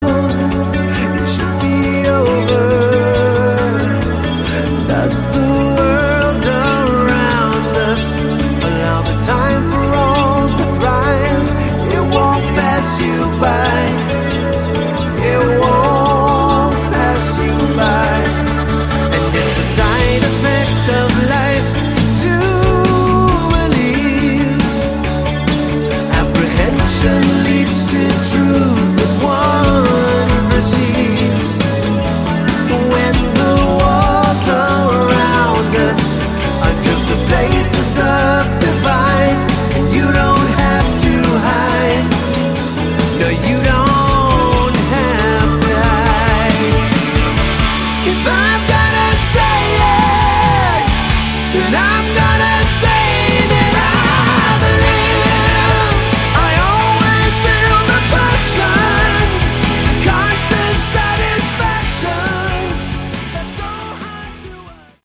Category: Melodic Rock
bass, lead vocals
drums
guitars
keyboards
piano, additional keyboards